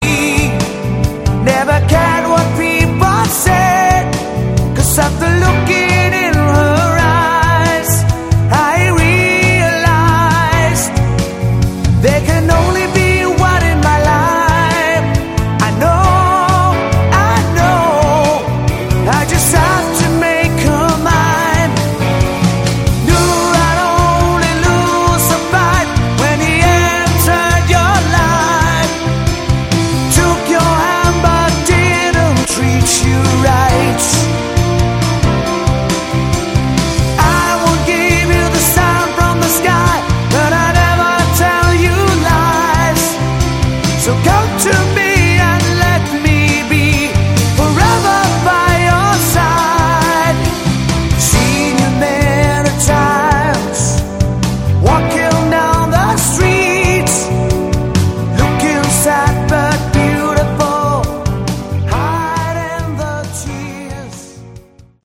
Category: AOR
Demo